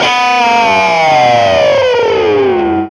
Index of /90_sSampleCDs/Roland L-CD701/GTR_GTR FX/GTR_E.Guitar FX